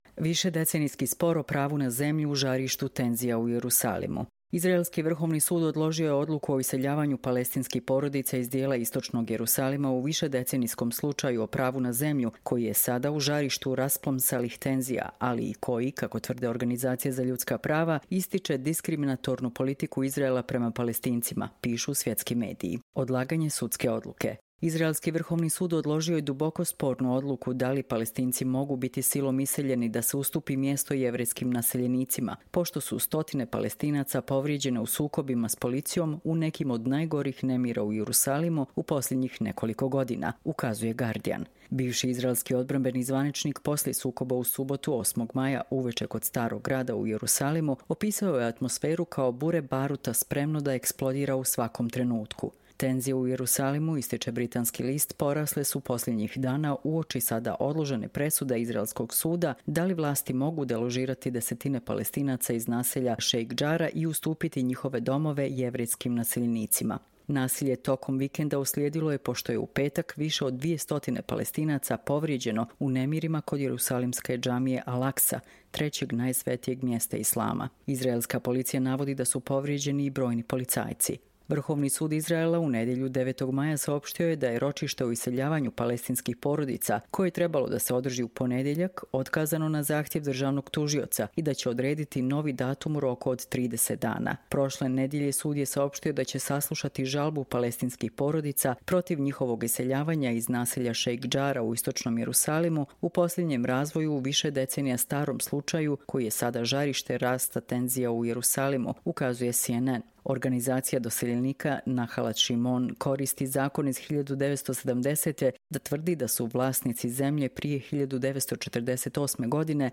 Čitamo vam: Višedecenijski spor o pravu na zemlju u žarištu tenzija u Jerusalimu